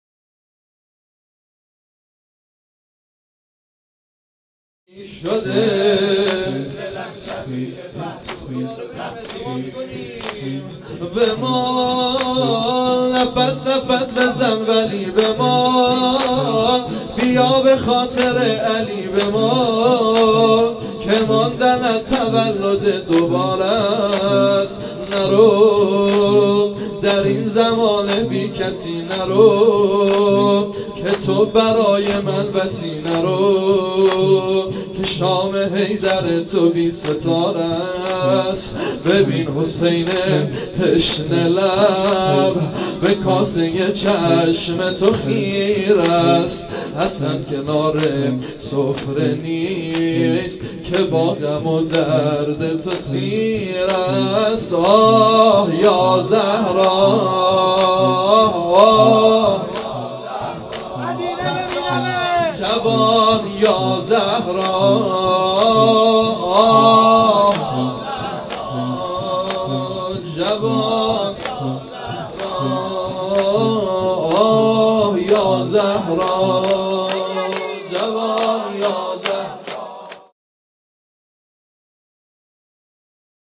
واحد | بمان نفس نفس بزن ولی بمان بیا به خاطر علی بمان
مداحی
شهادت حضرت زهرا(س)